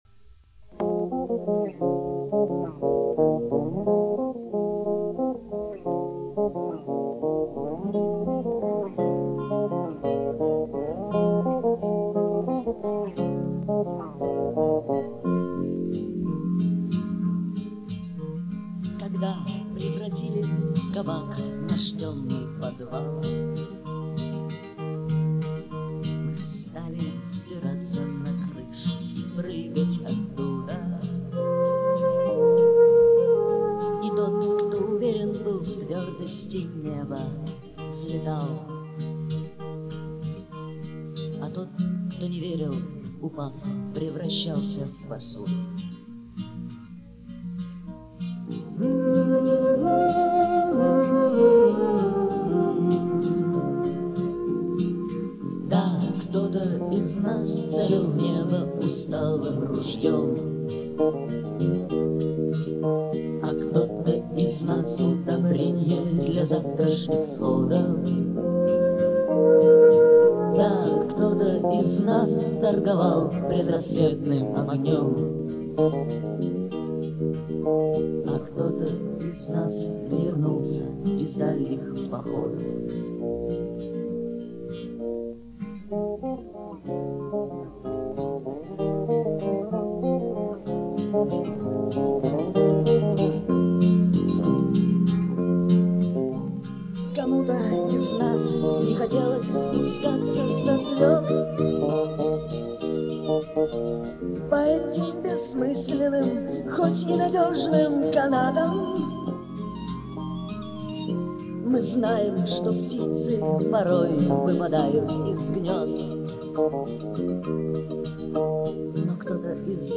Барнаульская рок-группа
Первая и лучшая запись на магнитофонной кассете.